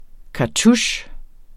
Udtale [ kɑˈtuɕ ]